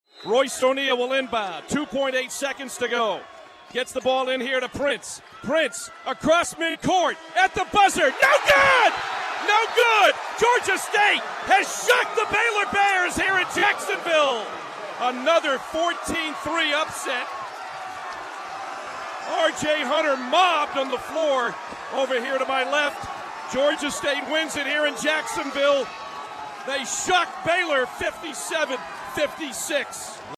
Baylor Final Call
FINAL CALL - GSU UPSETS BAYLOR 57-56.mp3